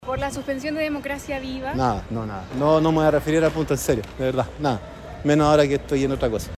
En el Ministerio Público, en tanto, estuvieron en contra de la reapertura de la investigación. Sin embargo, el fiscal regional de Antofagasta, Juan Castro Bekios, no quiso responder cuando fue abordado a la salida del Consejo de Fiscales.